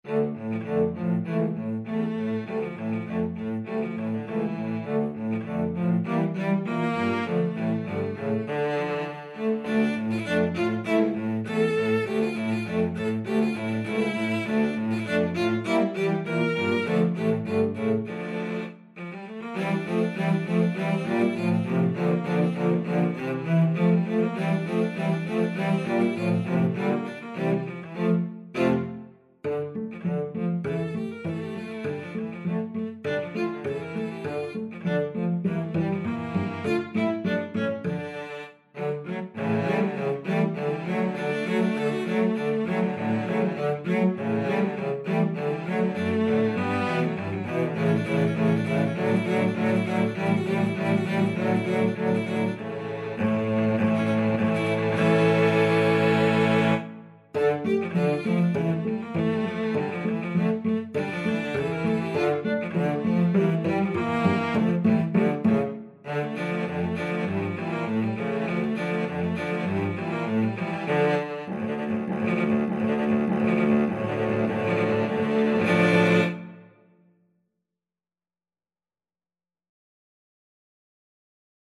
Free Sheet music for Cello Quartet
D minor (Sounding Pitch) (View more D minor Music for Cello Quartet )
2/4 (View more 2/4 Music)
Allegretto
Cello Quartet  (View more Intermediate Cello Quartet Music)
Classical (View more Classical Cello Quartet Music)